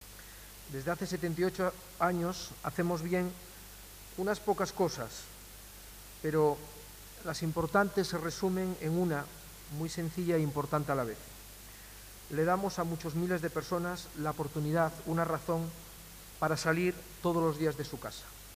al término de la entrega de galardones en un abarrotado salón de actos del CDC.
En su intervención